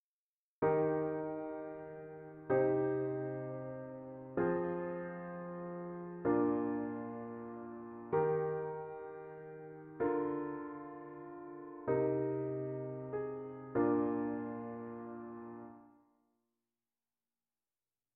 Ex. 2a. The gestures in phrase 1
Both descend a fourth over four structural pitches.